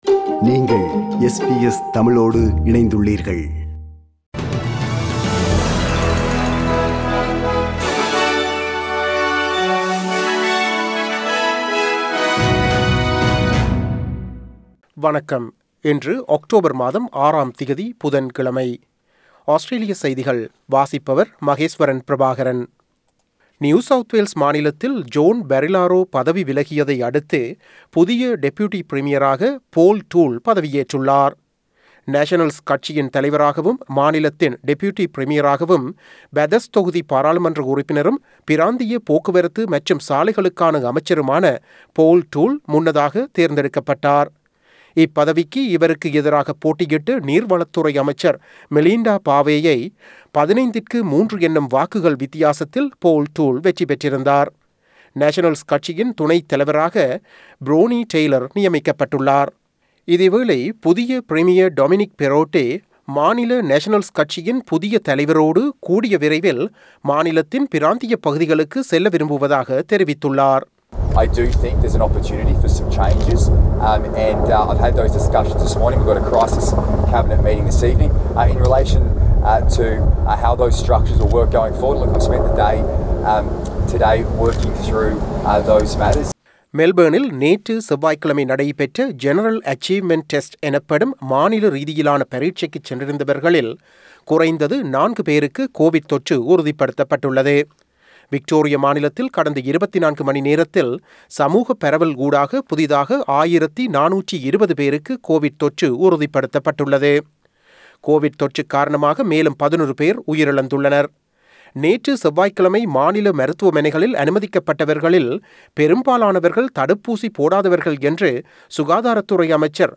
Australian news bulletin for Wednesday 06 October 2021.